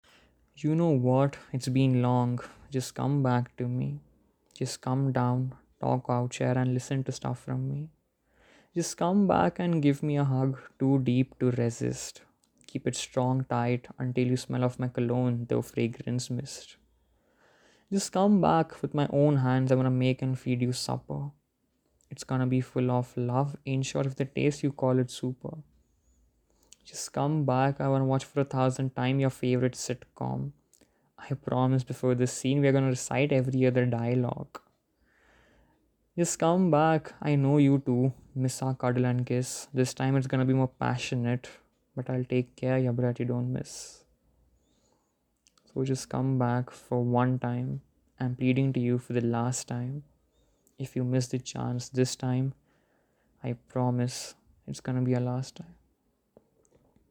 As you know the drill, I always prefer to recite my pieces and hence, would request you to download/play the audio, simultaneously while reading the lyrics, for the best experience.